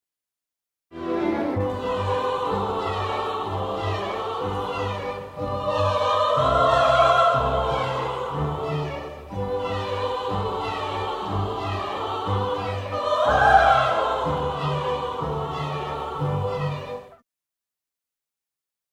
Pyotr Il’yich Tchaikovsky (1840–93) used dramatic vocalization in the form of an offstage wordless chorus to signify the supernatural in the “Waltz of the Snowflakes,” the act 1 finale from his ballet The Nutcracker (1892).
The lyrical, G-major, diatonic chorus provides the audience with a supernatural signifier, allowing for such a change of dramatic location.
National Philharmonic Orchestra
Originally produced for broadcast in the US by CBS on December 16, 1977